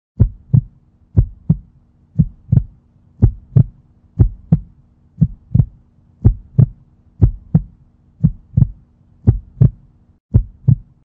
دانلود صدای ضربان قلب از ساعد نیوز با لینک مستقیم و کیفیت بالا
جلوه های صوتی